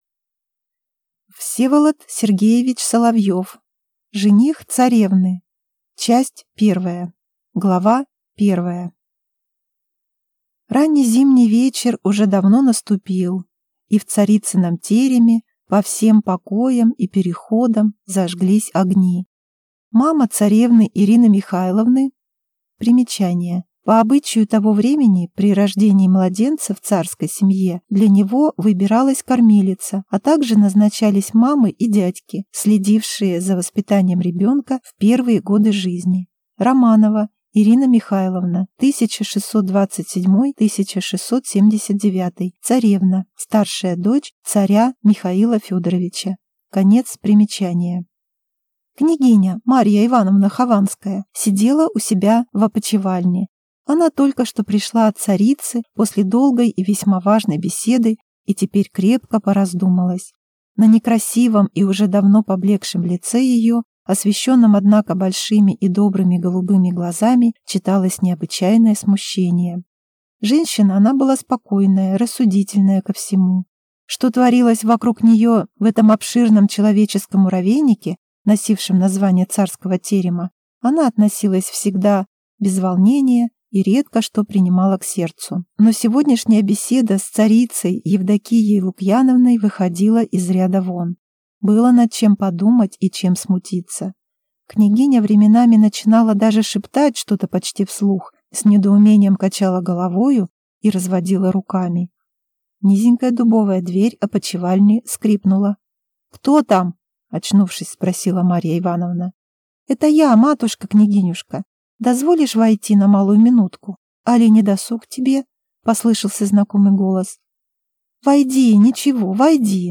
Aудиокнига Жених царевны